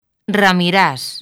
Transcripción fonética
ramiˈɾas